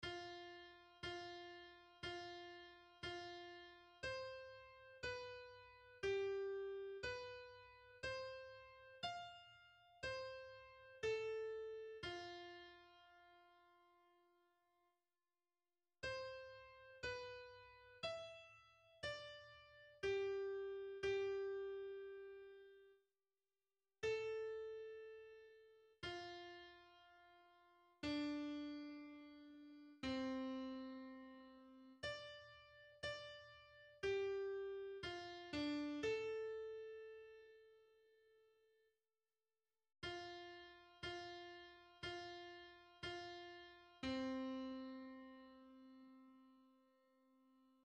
Komposition gibt es eine Midi Datei zum Hören und eine PDF Datei.